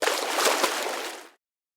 Swimming Single Stroke One Shot
SFX
yt_J4Q-P76HHPc_swimming_single_stroke_one_shot.mp3